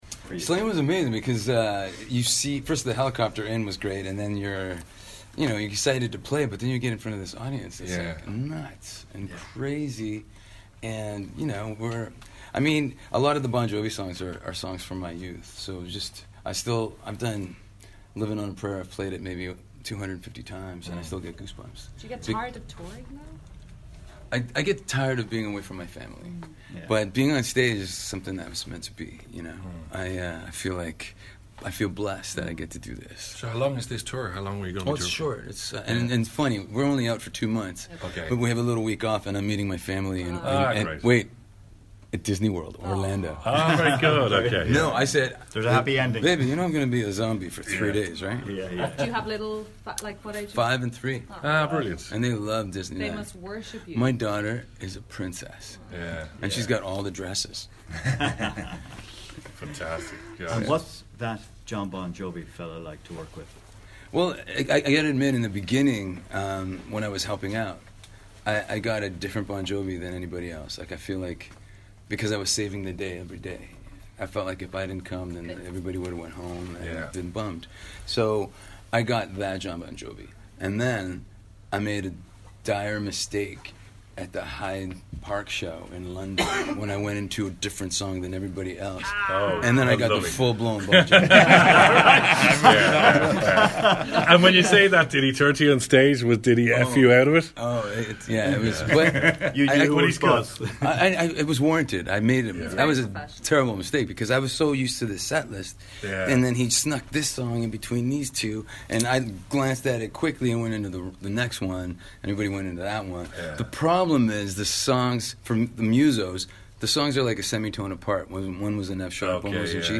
This will be their first Irish show since 2013 when the band played at Slane Castle. Lead guitarist Phil X, who joined the band on that tour, recalls Ireland well: